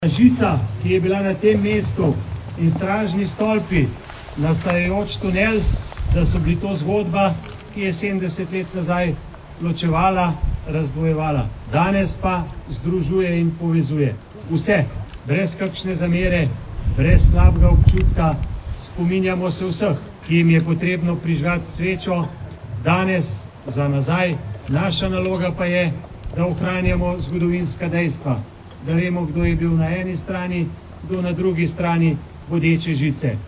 V Spominskem parku podružnice koncentracijskega taborišča Mauthausen, je danes dopoldne potekala spominska slovesnost ob 70. obletnici osvoboditve koncentracijskega taborišča pod Ljubeljem, ki so jo pripravile Občina Tržič, Zveza združenj borcev za vrednote NOB Slovenije in Zveza borcev za vrednote NOB Tržič.
“Žica, stražni stolpi, nastajajoči tunel so bili zgodba, ki je 70 let nazaj  ločevala, razdvojevala. Danes pa združuje in povezuje.  Vse nas, brez zamere in slabega občutka.  Spominjamo se vseh, ki jim je potrebno prižgati svečo danes, za nazaj. Naša naloga pa je, da  ohranjamo zgodovinska dejstva, da vemo, kdo je bil na eni in kdo na drugi strani bodeče žice. Zato sem še posebej ponosen na sodelovanje z našimi sosedi, ki združeni skupaj  kažemo pot novi Evropi. Ta ima težave, a  tako kot pred sedemdesetimi leti, bo in mora biti tudi tokrat zmagovalec,” je v pozdravu zbrane nagovoril župan Občine Tržič mag. Borut Sajovic.
42658_trziskizupanmag.borutsajovicnaslovesnostipodljubeljem.mp3